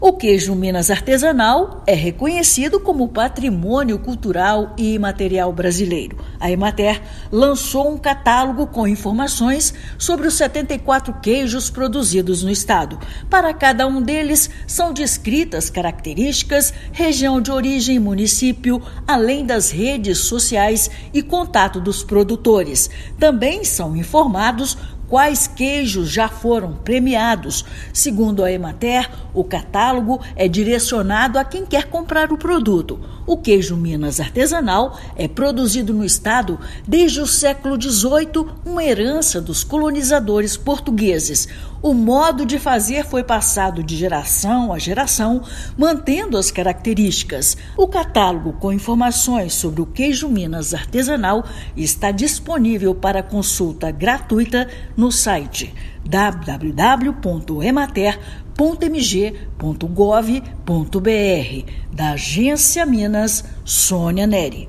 Documento contém informações históricas e regiões produtoras. Ouça matéria de rádio.